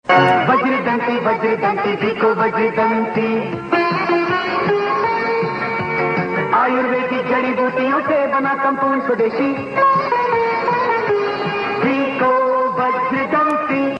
Famous Old TV Ads Ringtones